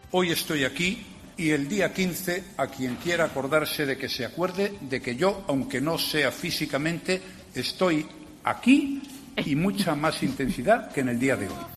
Esta es la última pifia de Rajoy en un mitin en Melilla